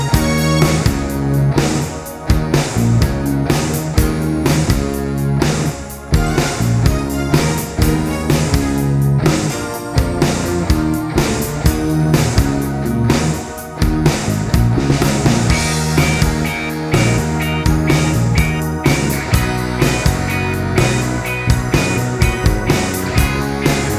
no Backing Vocals Soft Rock 4:05 Buy £1.50